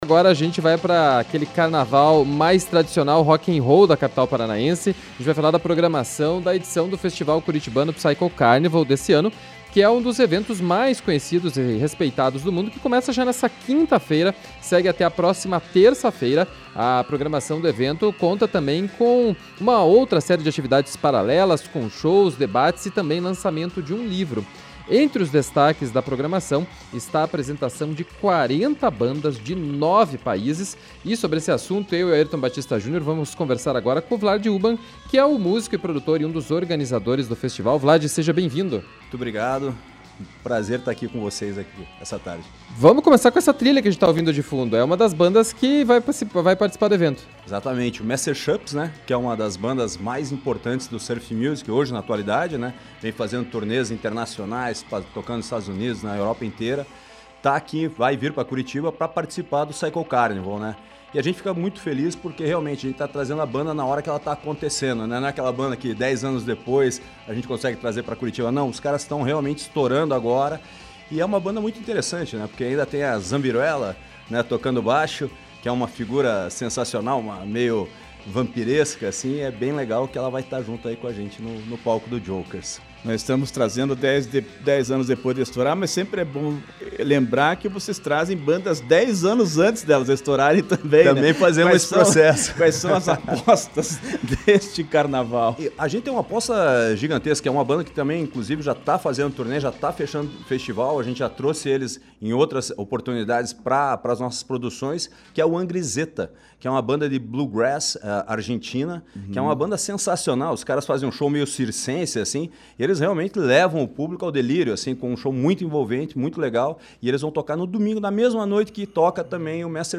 ENTREVISTA-PSYCHO-CARNIVAL.mp3